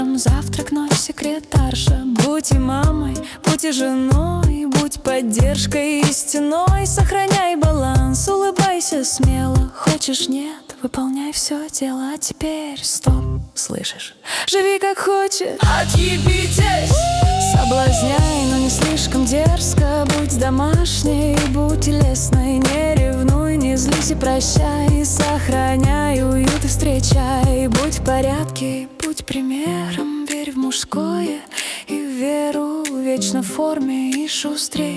Vocal Dance